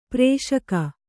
♪ prēṣaka